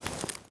Babushka / audio / sfx / UI / SFX_InvClose_01.wav
SFX_InvClose_01.wav